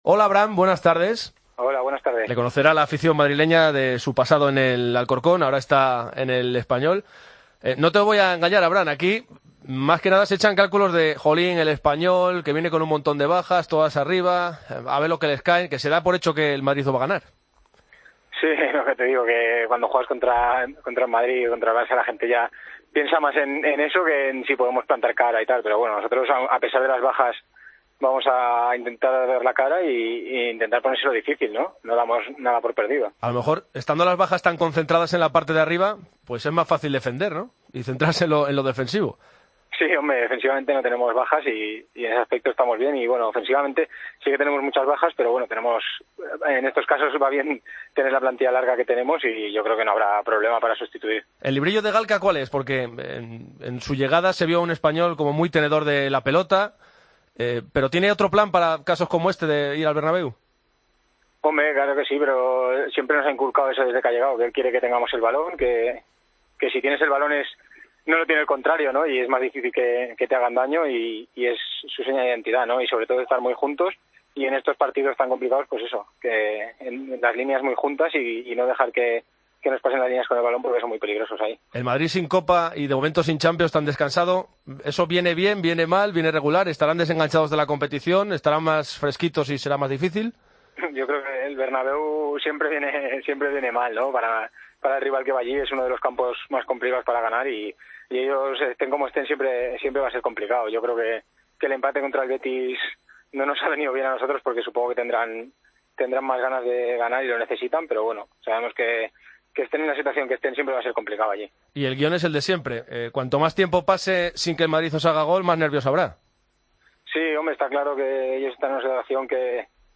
El centrocampista del Espanyol analizó en Deportes COPE el choque de su equipo ante el Madrid de este domingo. Abraham explicó que el equipo saldrá "a tener el balón" y a "estar juntos para que no nos pasen las líneas".